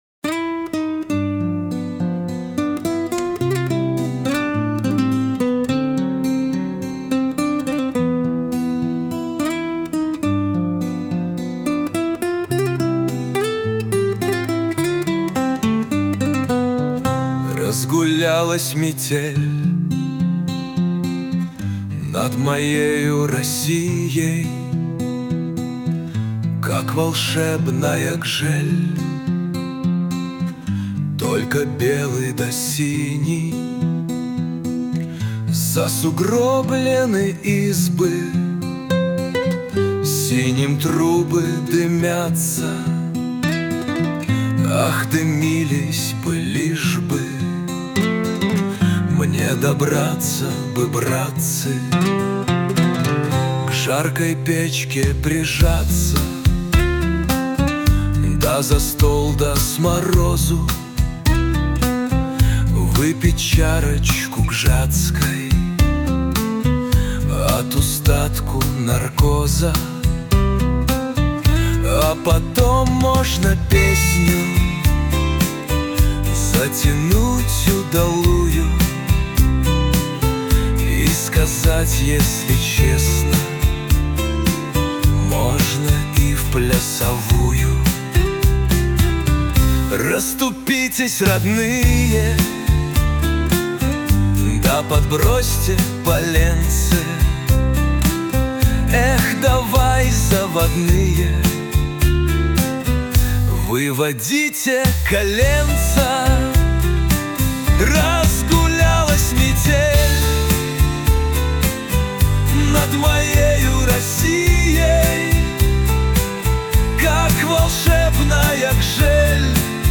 • Аранжировка: Ai
• Жанр: Романс